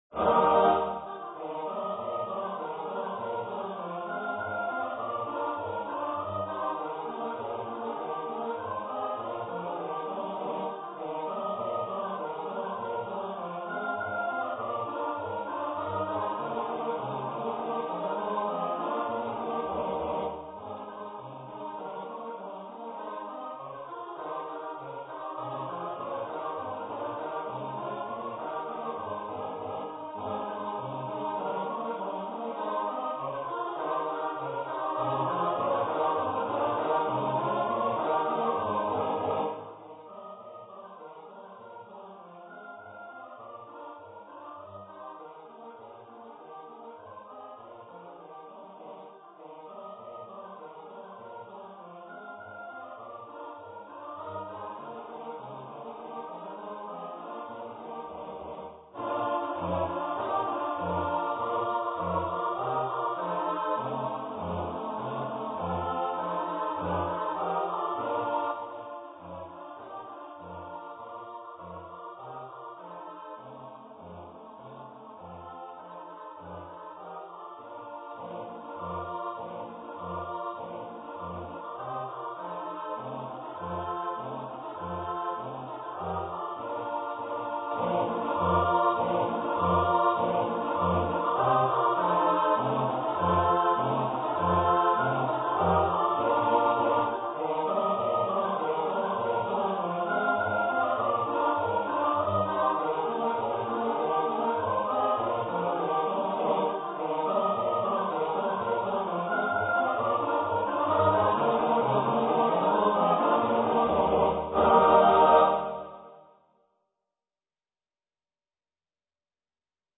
for unaccompanied mixed voice choir
Choir - Mixed voices (SATB)